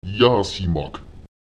Lautsprecher yásimak [Èjaùsimak] die Feuerstelle, das Feuer (etwas, das erwärmt)